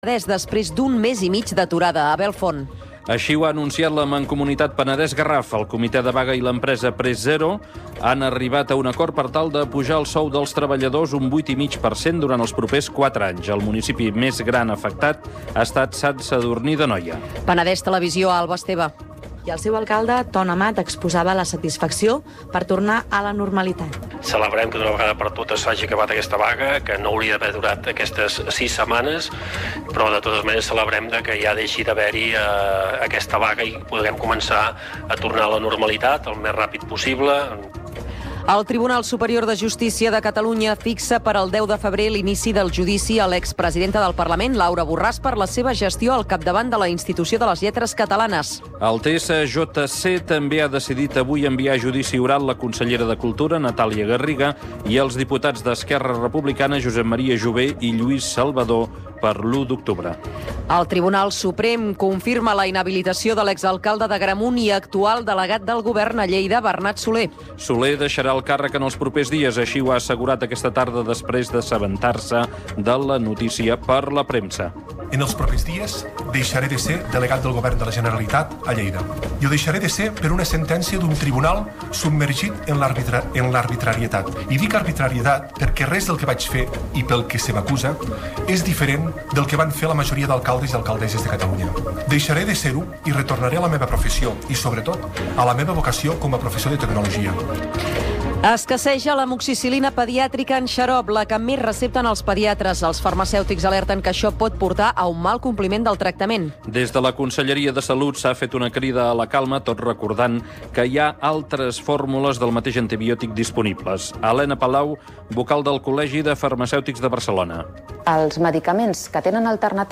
Informatius